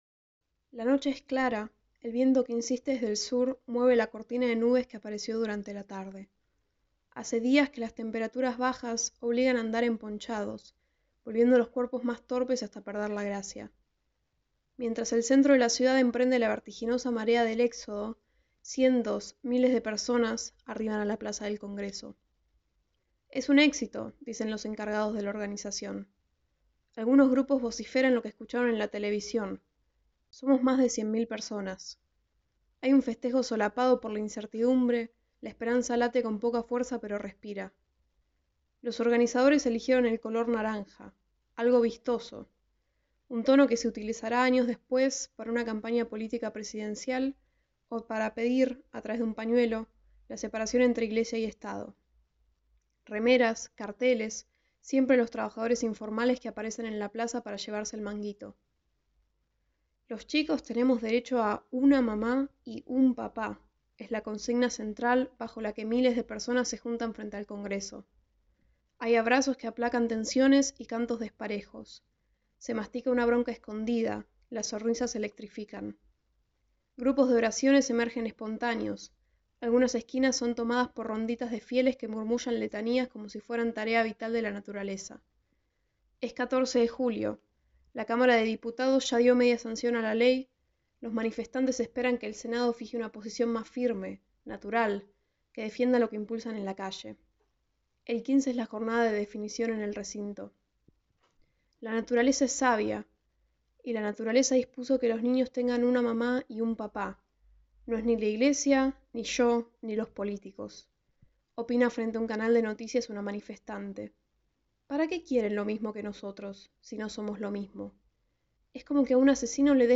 narrado por